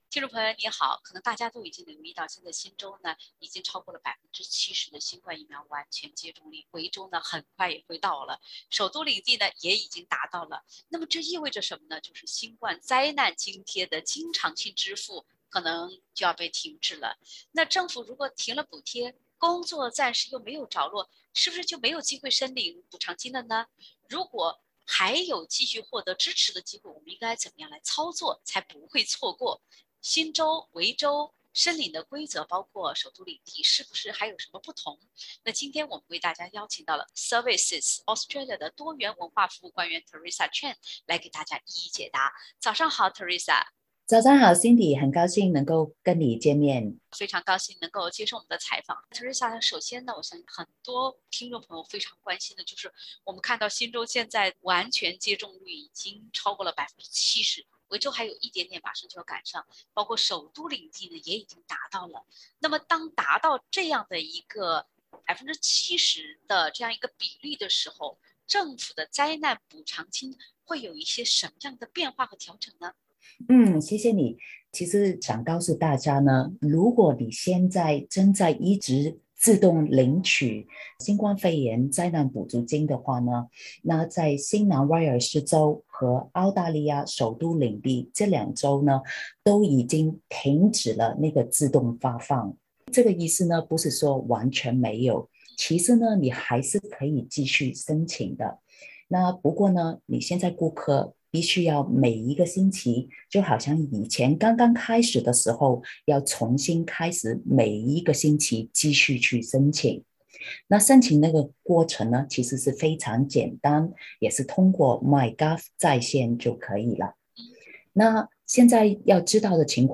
services Australia多元文化服務官員講解解封後新冠災難津貼調整。（點擊封面圖片，收聽完整寀訪）